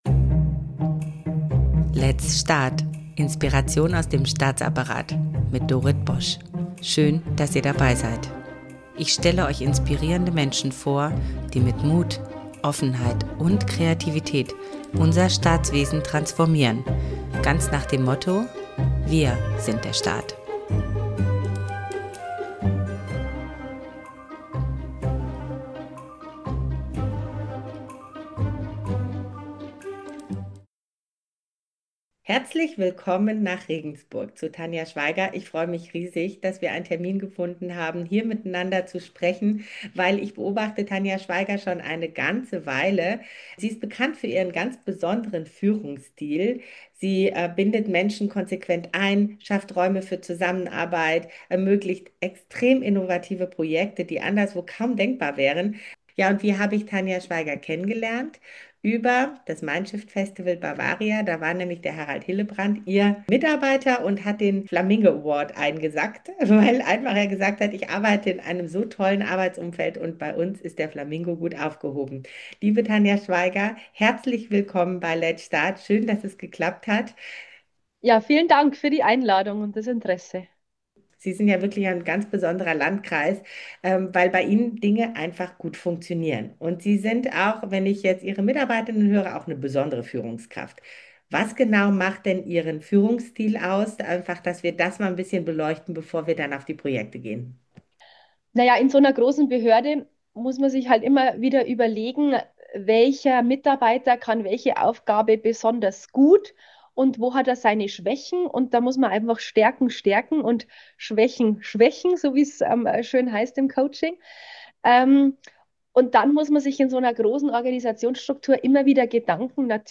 In dieser Episode spreche ich mit Tanja Schweiger, Landrätin des Landkreis Regensburg, über ihre Organisationsstrukturen, digitale Spitzenleistungen im ländlichen Raum und die Kraft vernetzter Zusammenarbeit.